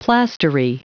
Prononciation du mot plastery en anglais (fichier audio)
Prononciation du mot : plastery